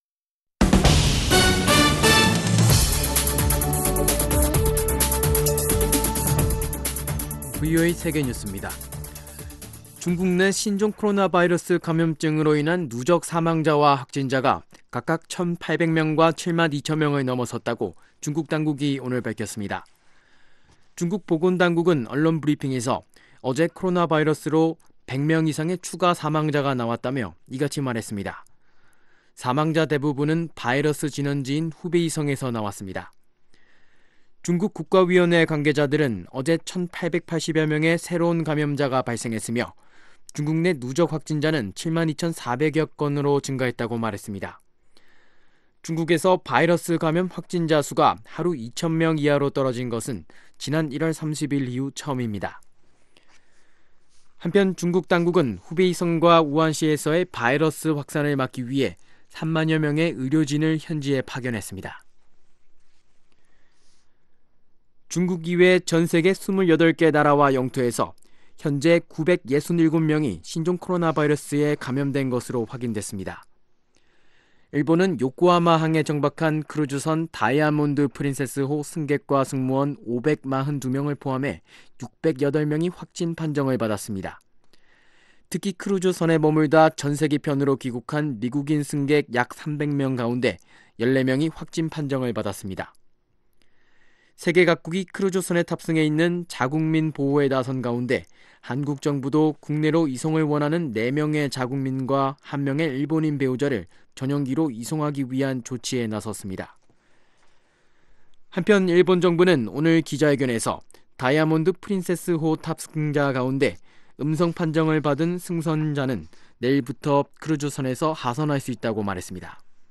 VOA 한국어 간판 뉴스 프로그램 '뉴스 투데이', 2019년 2월 18일 2부 방송입니다. 미국과 한국의 국방장관이 다음주 워싱턴에서 회담을 갖습니다.